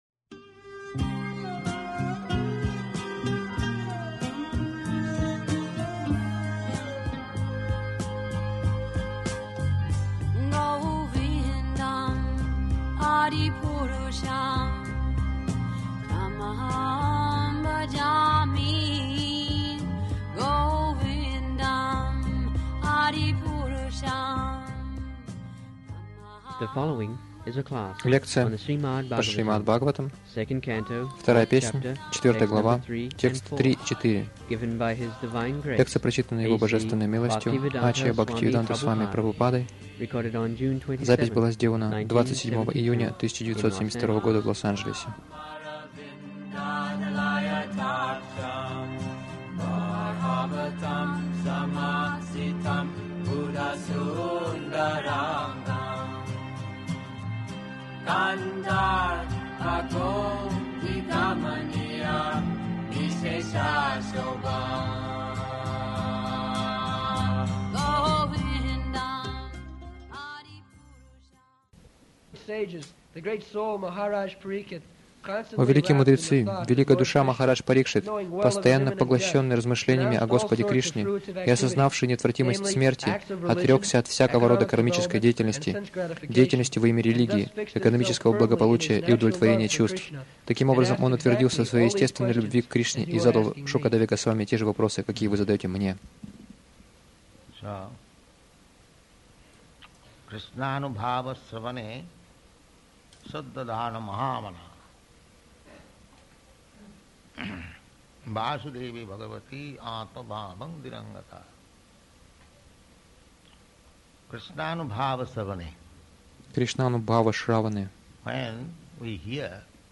Лекции Шрилы Прабхупады по Шримад-Бхагаватам
Prabhupada-lecture-on-Srimad-Bhagavatam-2.4.3-4.mp3